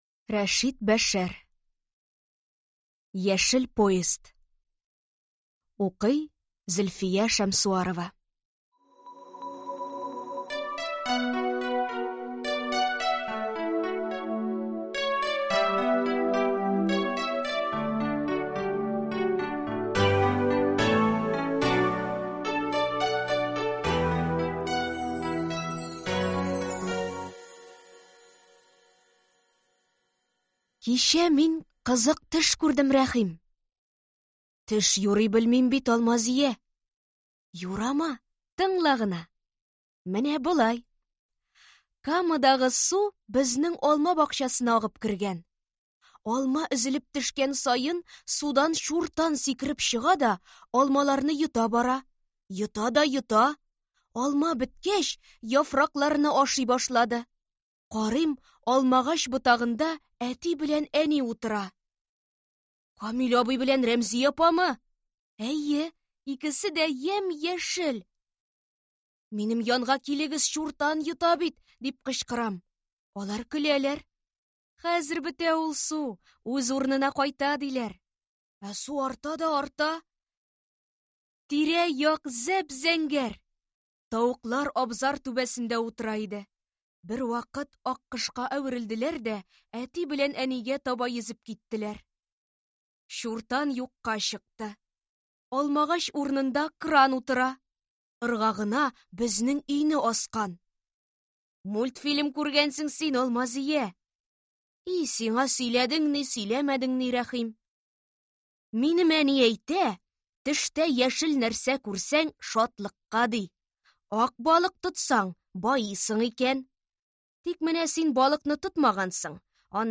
Аудиокнига Яшел поезд | Библиотека аудиокниг
Прослушать и бесплатно скачать фрагмент аудиокниги